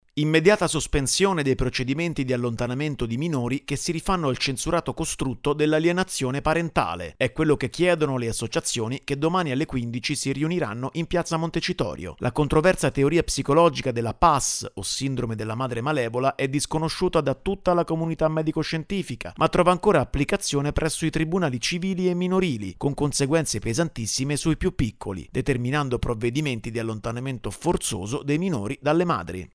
Domani a piazza Montecitorio a Roma il presidio per rimettere al centro l’ascolto dei minori. Il servizio